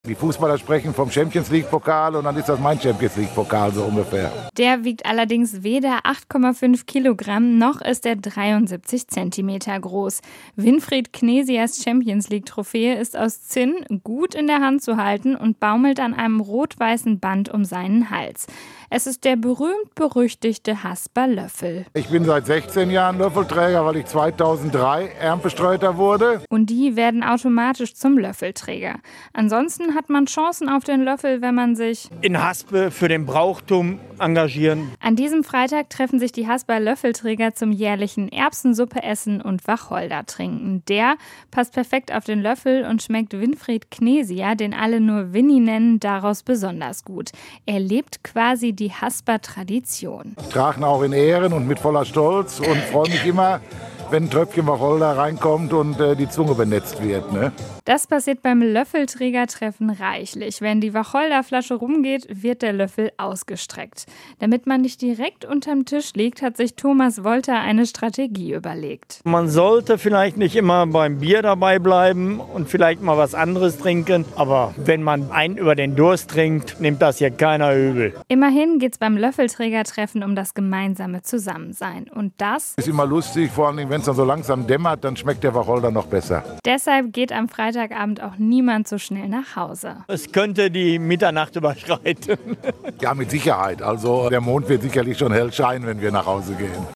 So läuft es beispielsweise bei den Hasper Löffelträgern. Einmal im Jahr gibt es ein großes Treffen - nur für Mitglieder.